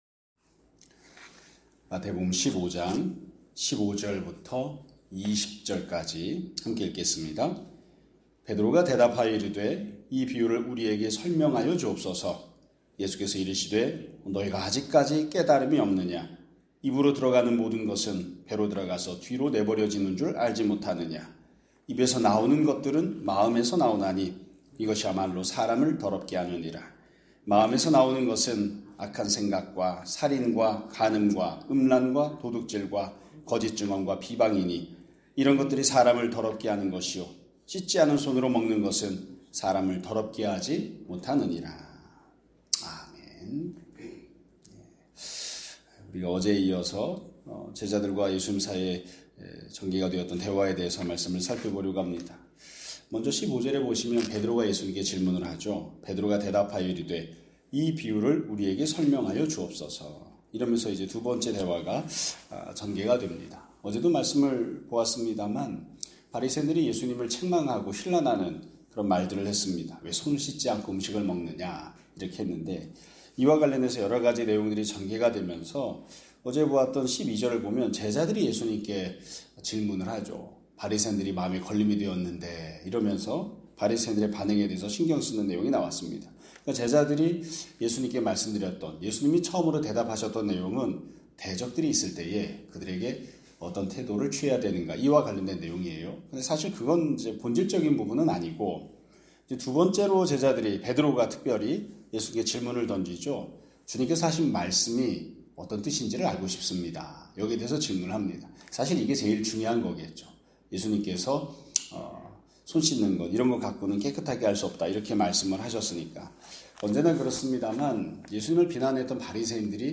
2025년 11월 4일 (화요일) <아침예배> 설교입니다.